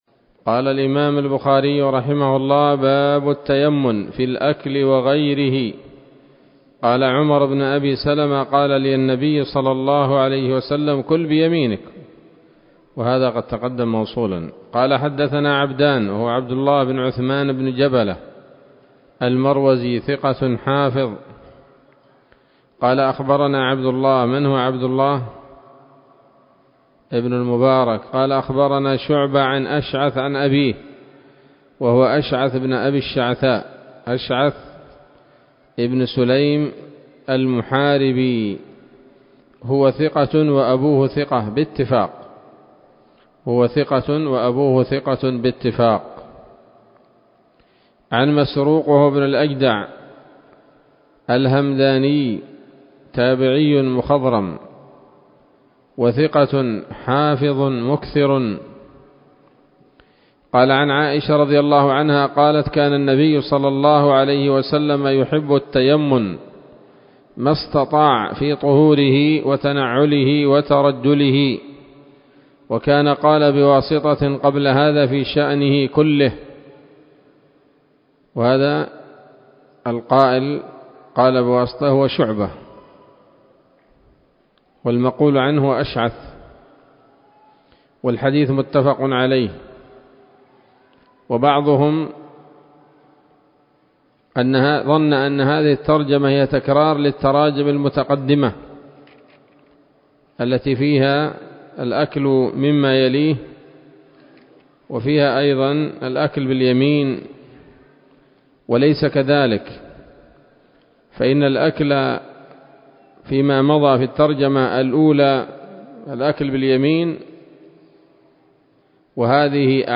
الدرس الرابع من كتاب الأطعمة من صحيح الإمام البخاري